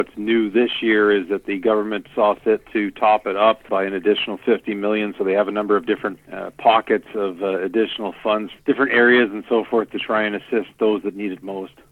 Pembroke Mayor Ron Gervais says the additional 50 million is welcome news to municipalities who really need the boost: